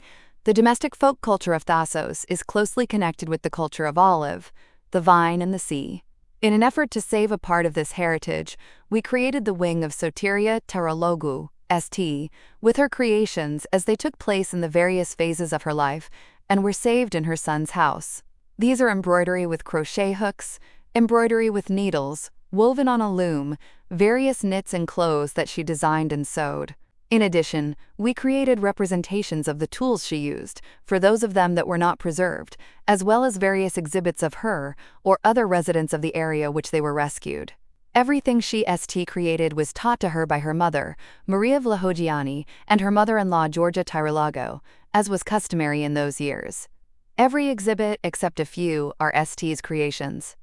Audio guided tour